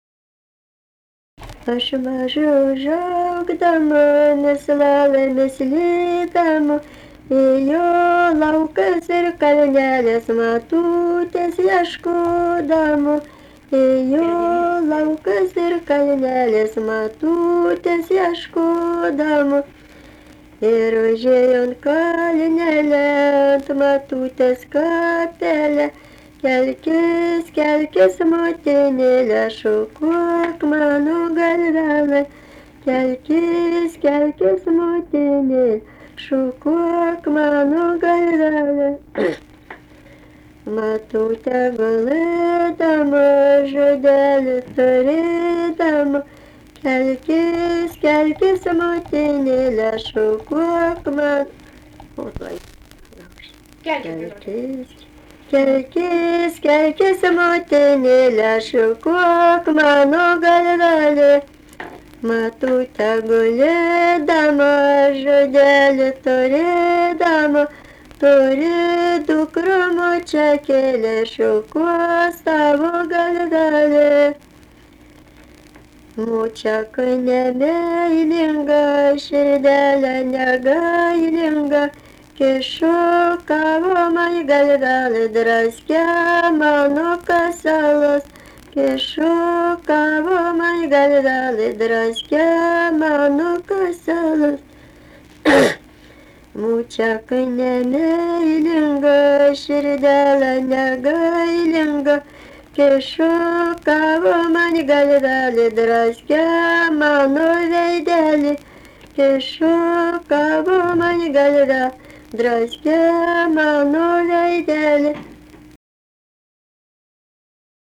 daina
Mantvydai
vokalinis